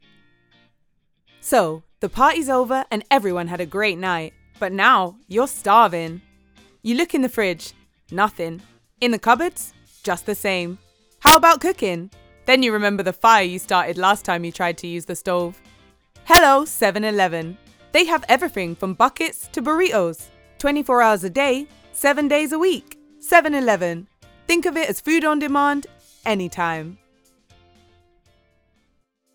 Female
English (British)
Warm, friendly and a little husky, my voice is authoritative but approachable. The range is naturally alto although is comfortable pitching higher, for example for a more energetic teen sound.
Urban
Young Urban British Commercial
Words that describe my voice are warm, husky, friendly.